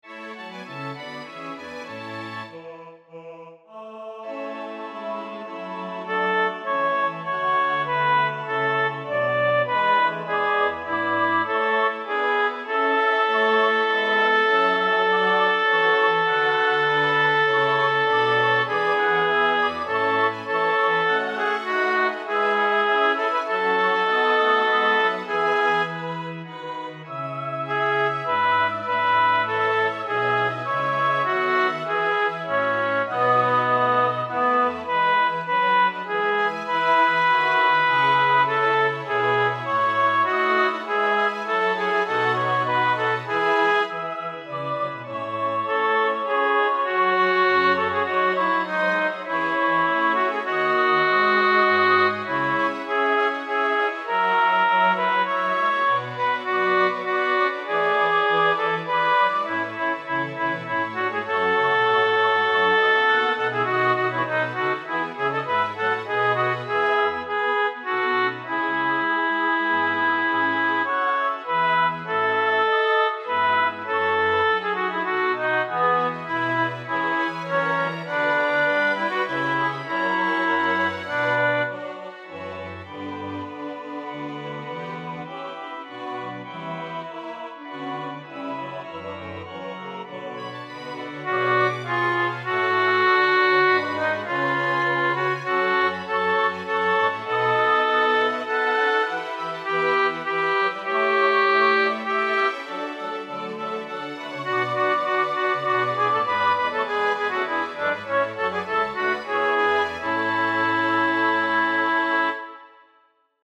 J-S-Bach-Kantat-37-Alt.mp3